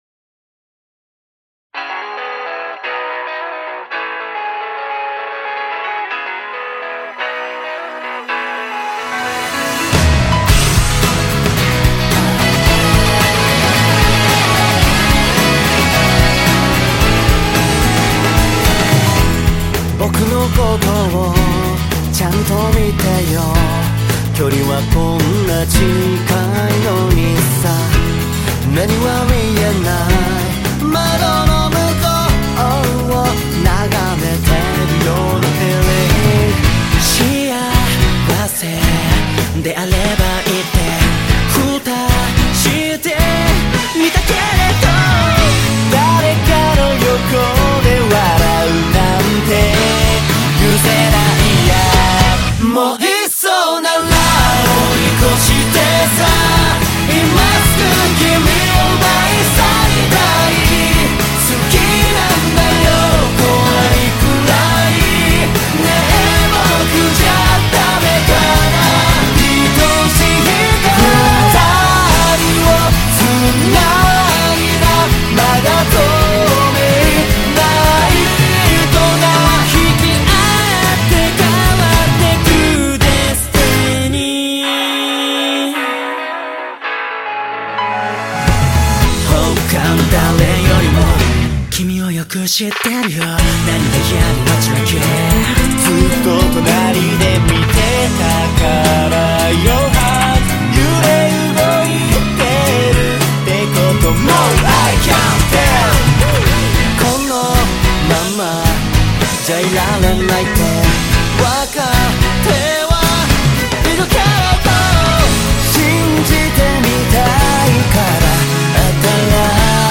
Label JPop